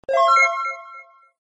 Success_Sound.mp3